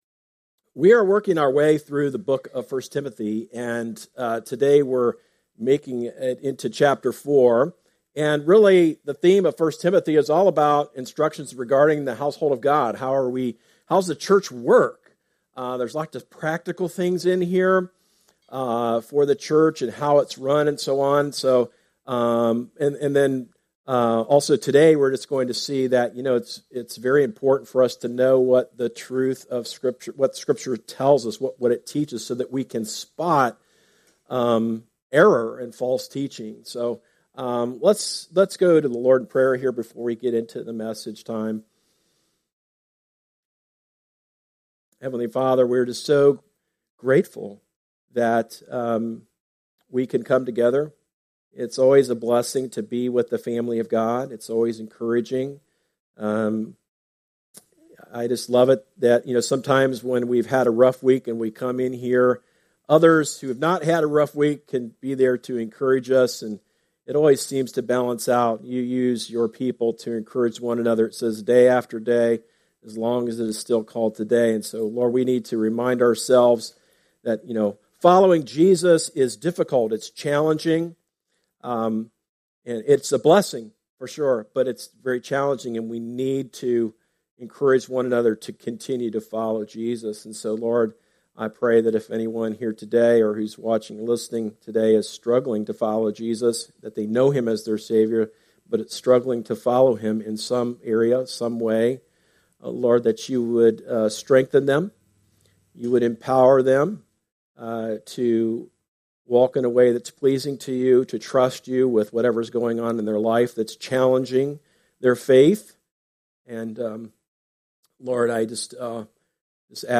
Sermons – Darby Creek Church – Galloway, OH